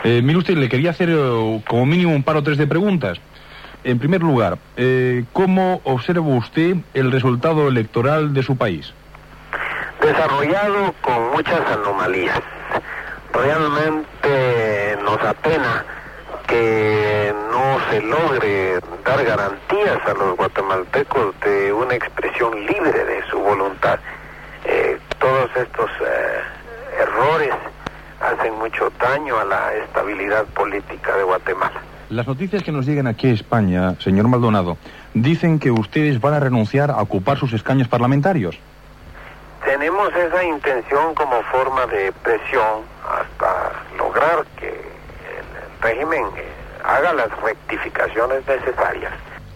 Fragment d'entrevista informativa a Alejandro Maldonado, candidat a les eleccions a Guatemala.
Informatiu